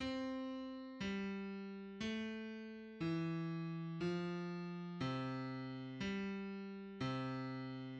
Falls by a 4th and rises by a step